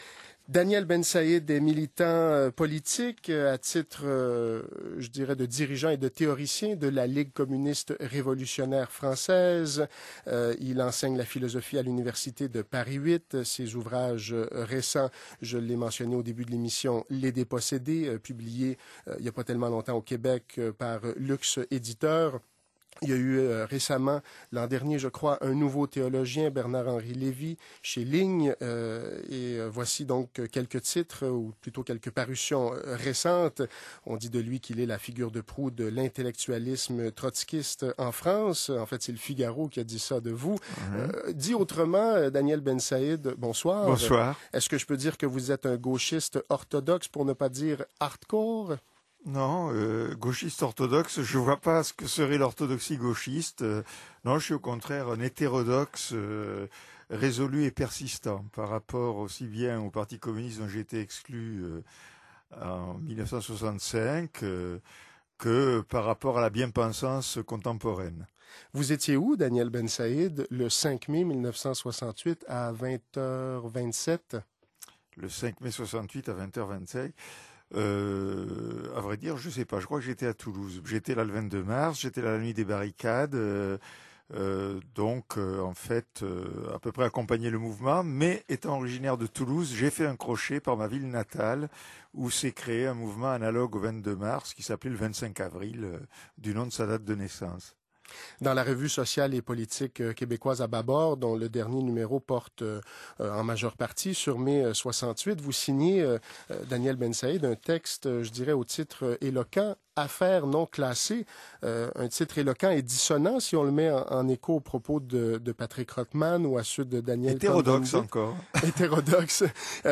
L’entretien radiophonique